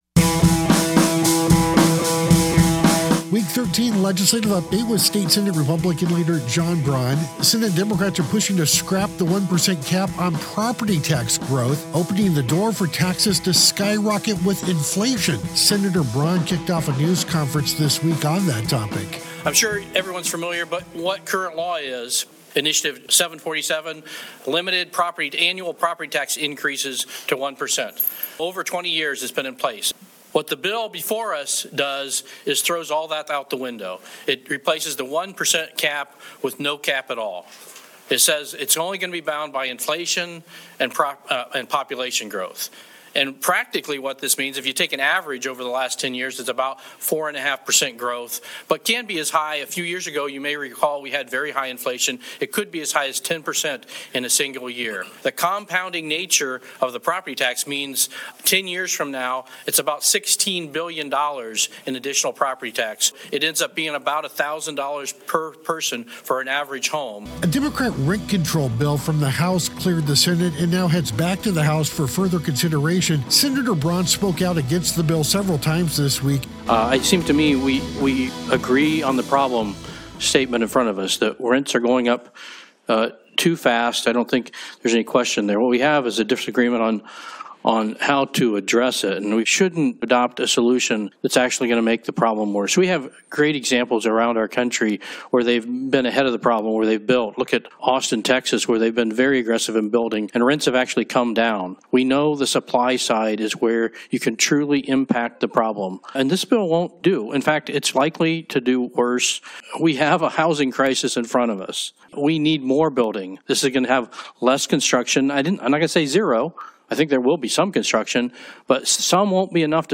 State Senate Democrats are pushing to scrap the one percent cap on property tax growth, opening the door for taxes to skyrocket with inflation. A Democrat rent control bill from the House cleared the Senate and now heads back to the House for further consideration. Senator Braun speaks out against both measures.